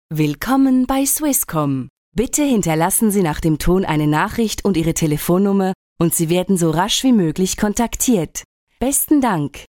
Telefonansage Hochdeutsch (CH)
Sprecherin mit breitem Einsatzspektrum.